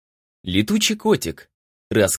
Аудиокнига Летучий котик. Рассказы о животных | Библиотека аудиокниг